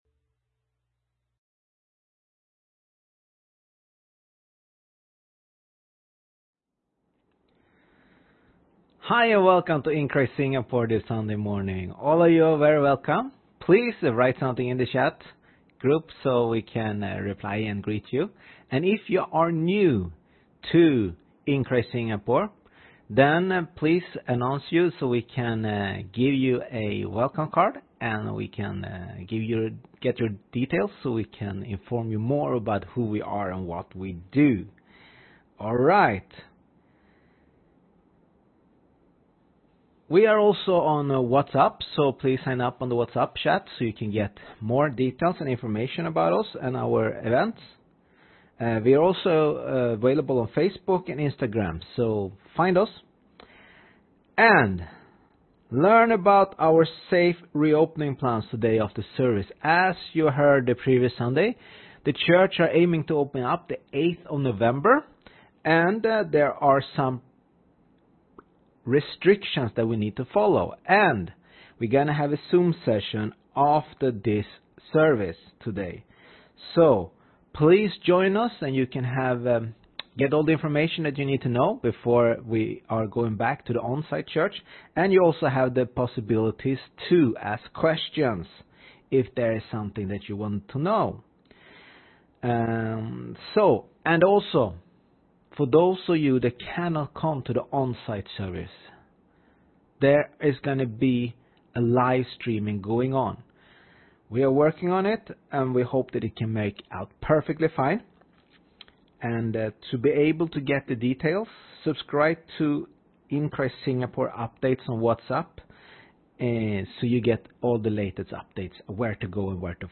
Sermon
10am service